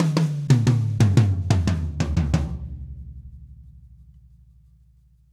Drumset Fill 17.wav